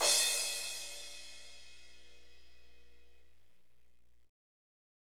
Index of /90_sSampleCDs/Northstar - Drumscapes Roland/DRM_Hip-Hop_Rap/CYM_H_H Cymbalsx